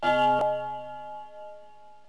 / cdmania.iso / sounds / bells / bigblrng.wav ( .mp3 ) < prev next > Waveform Audio File Format | 1996-04-15 | 27KB | 1 channel | 22,050 sample rate | 2 seconds